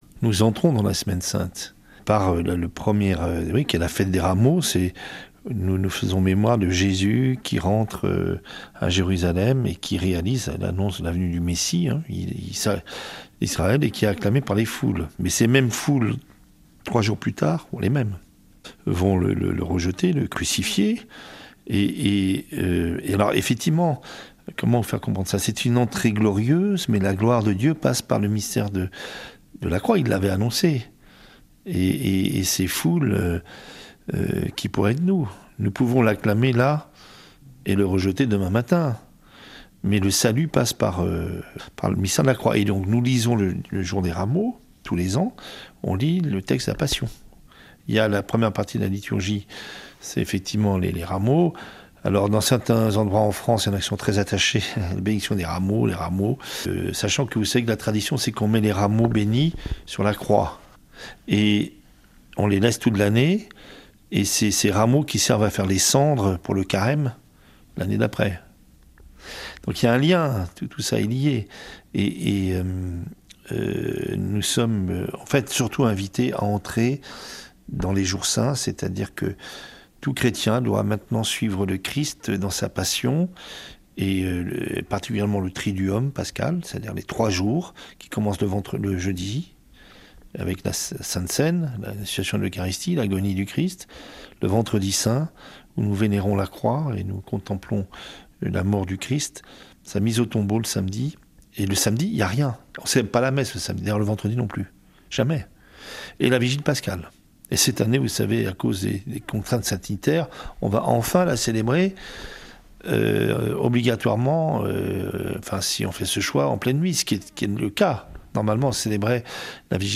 Mgr Le Saux dans "Actualités en Sarthe"